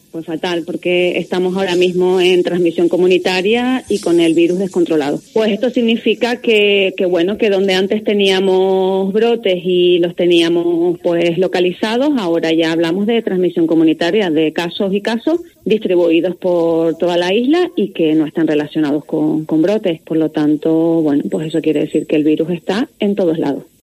Este lunes, en una entrevista en COPE Canarias, se reafirmaba y añadía que "el virus está descontrolado, ya hay transmisión comunitaria, casos y casos distribuidos por toda la isla y que no están relacionados con brotes, lo que quiere decir que el virus está en todos lados".